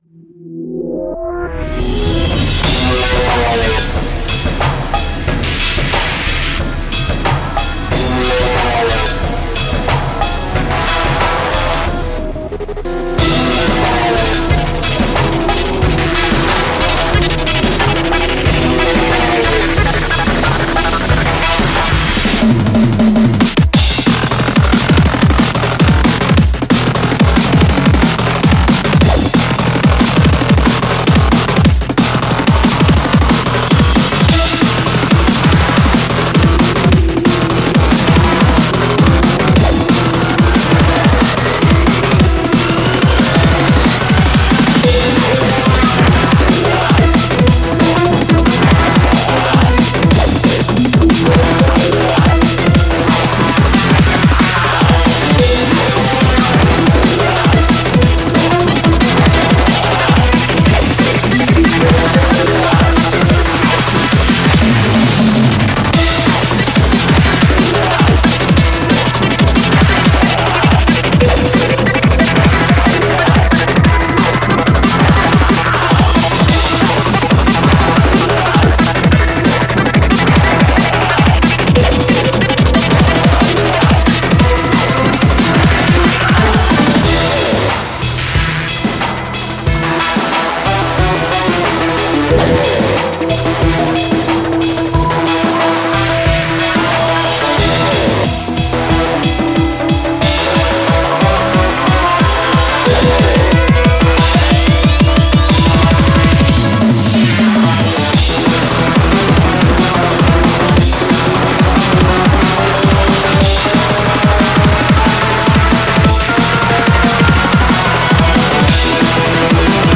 BPM45-182
Audio QualityPerfect (High Quality)